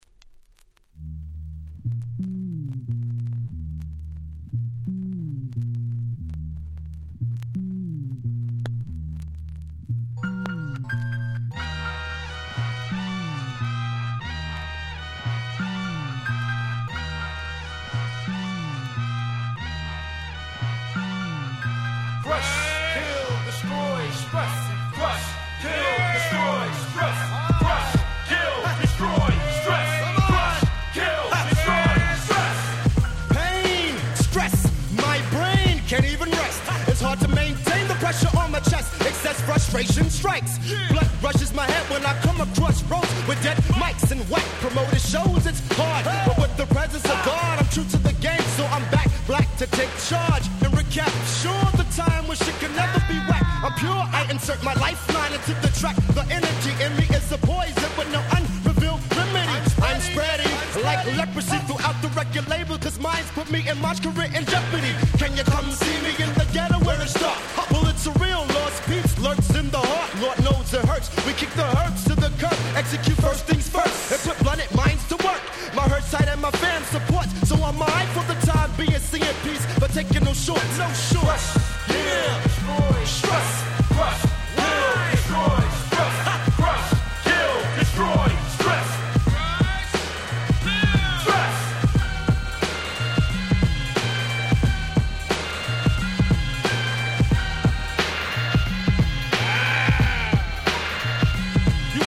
94' Smash Hit Hip Hop !!